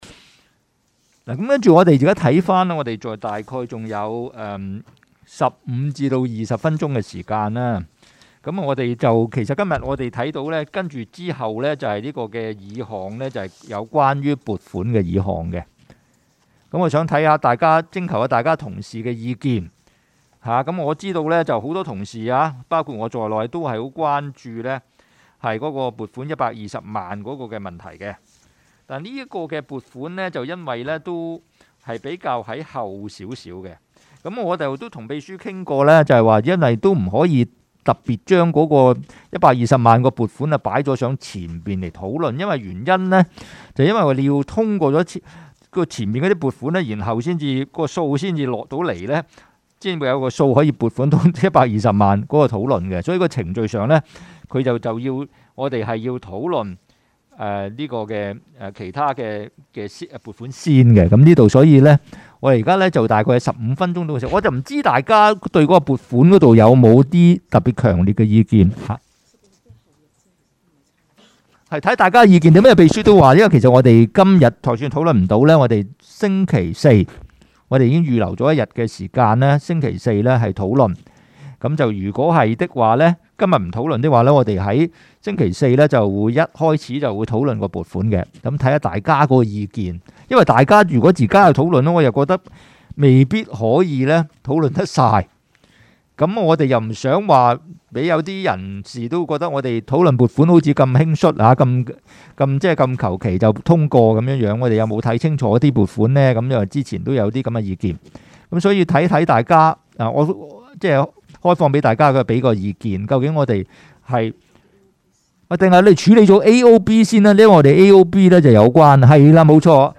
区议会大会的录音记录
地点: 九龙旺角联运街30号 旺角政府合署4楼 油尖旺区议会会议室